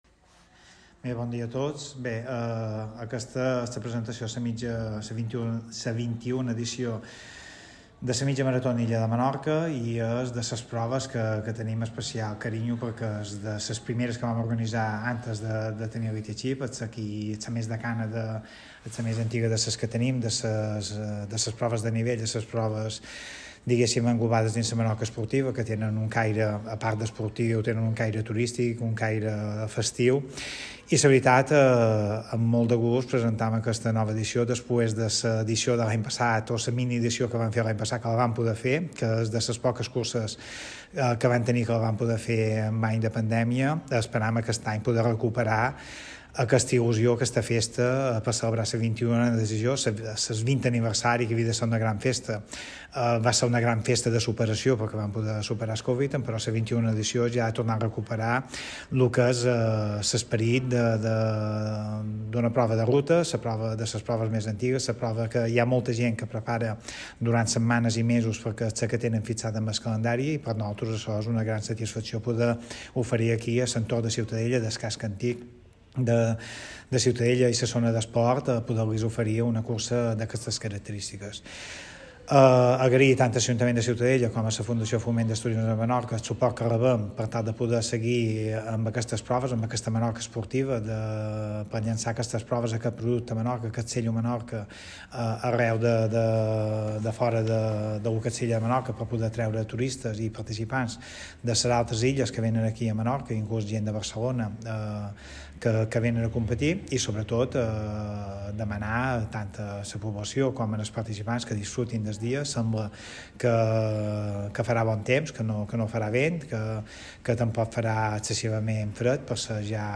La carrera se ha presentado este miércoles en el mismo ayuntamiento de Ciutadella